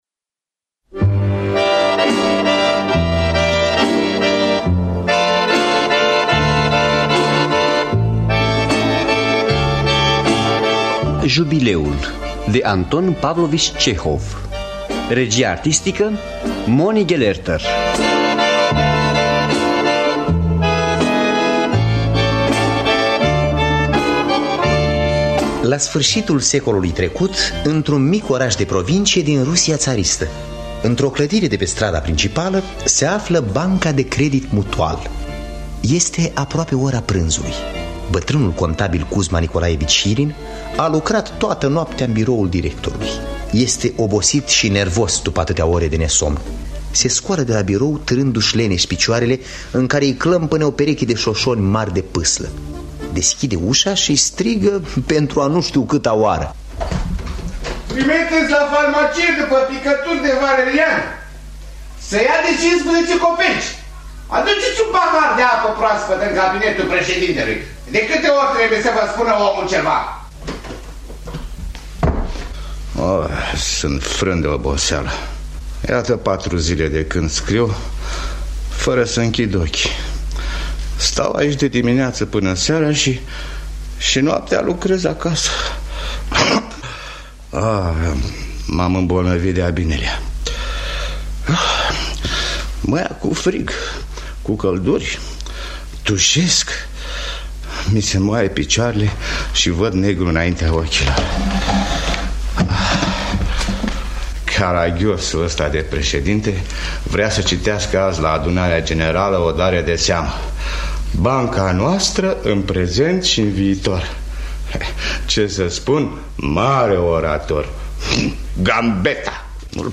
“Jubileul” de Anton Pavlovici Cehov – Teatru Radiofonic Online